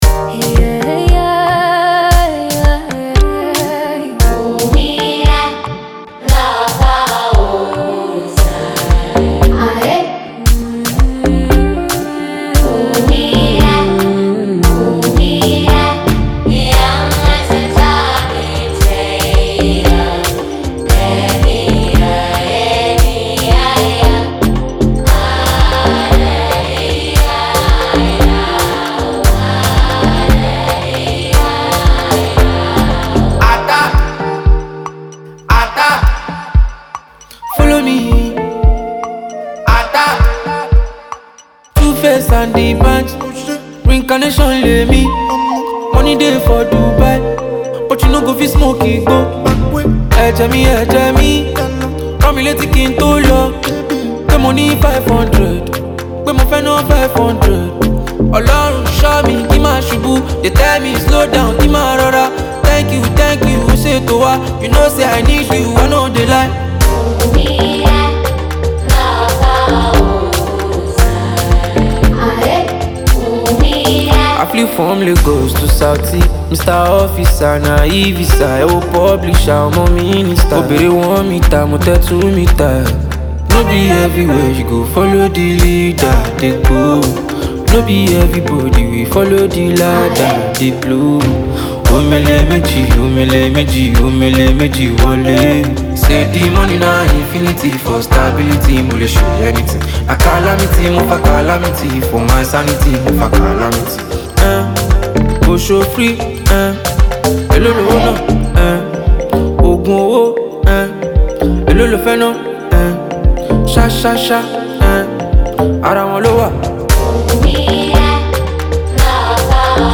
The beat is driven by punchy drums and a rhythmic bounce